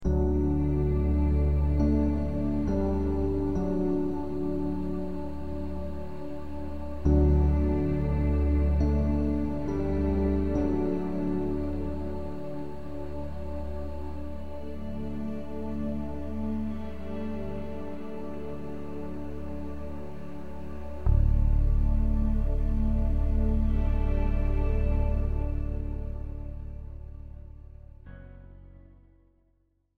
Classical and Opera
OPERA